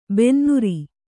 ♪ bennuri